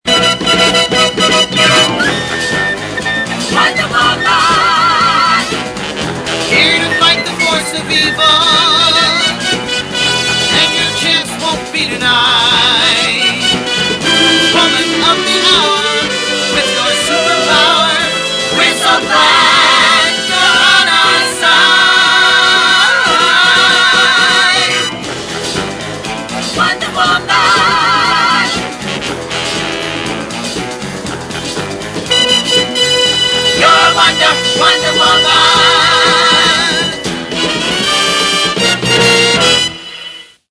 from the end credits.